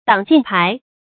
挡箭牌 dǎng jiàn pái
挡箭牌发音
成语注音ㄉㄤˇ ㄐㄧㄢˋ ㄆㄞˊ